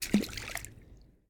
water-splash-14
bath bathroom bubble burp click drain dribble dripping sound effect free sound royalty free Nature